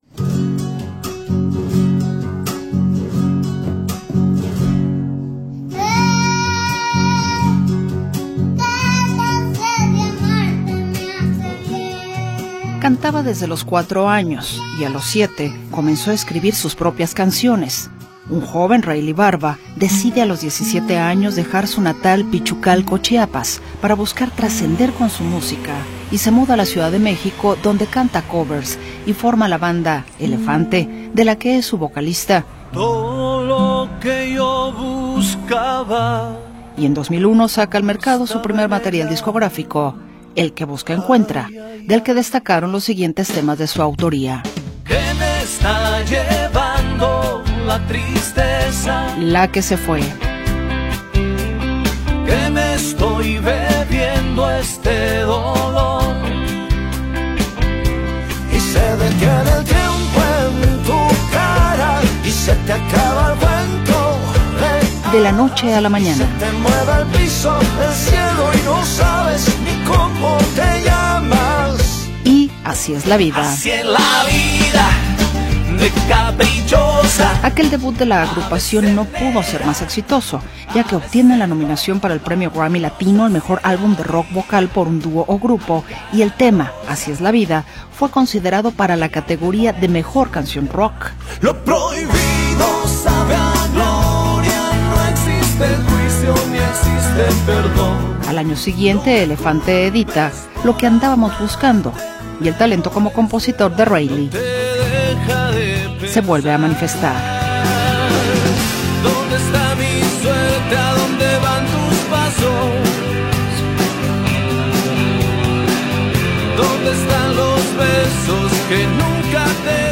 Reyli Barba, nacido en Chiapas el 12 de abril de 1972, es un cantautor mexicano que ganó fama como vocalista de Elefante antes de lanzarse como solista en 2003. Su estilo fusiona pop latino y balada romántica, destacando con éxitos como Desde que llegaste y Amor del bueno.